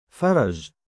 male_faraj.mp3